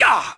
vs_fxanxxxx_hit2.wav